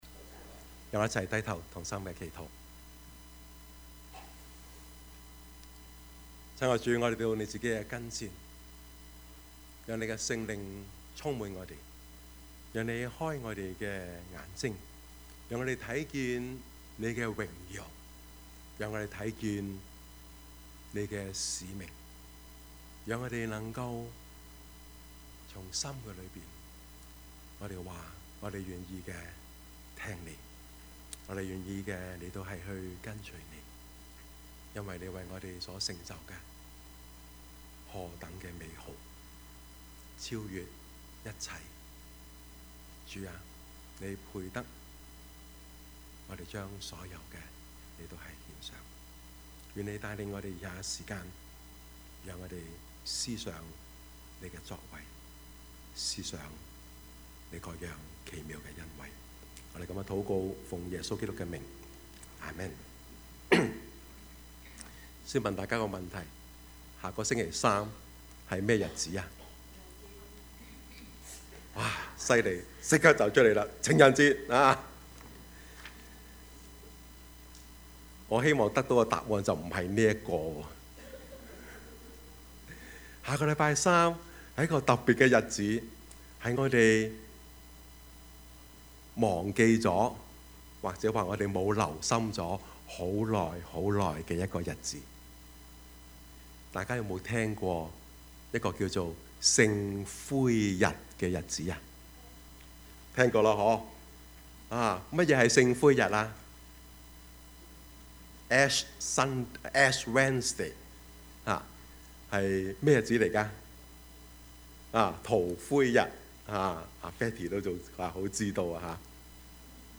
Service Type: 主日崇拜
Topics: 主日證道 « 捕風捉影的人生 吃喝玩樂，然後…..